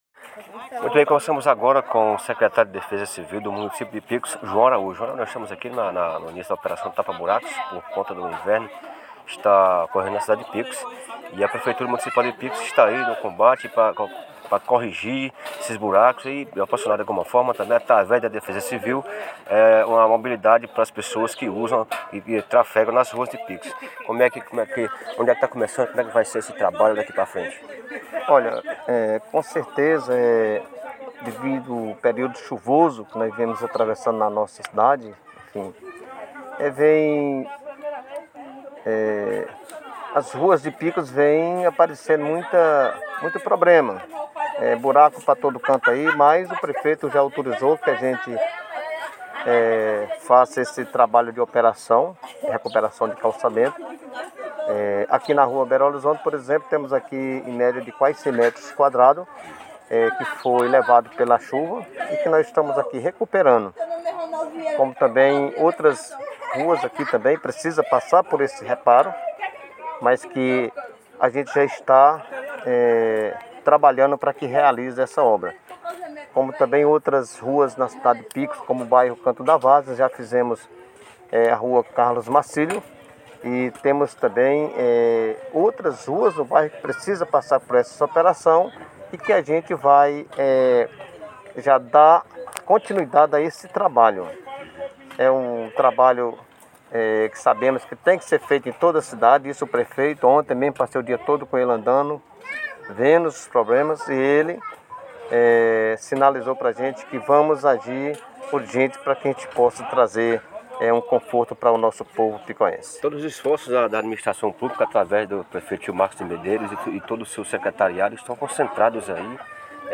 Sonora-João-Araújo-1.mp3